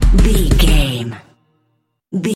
Aeolian/Minor
synthesiser
drum machine
funky
aggressive
hard hitting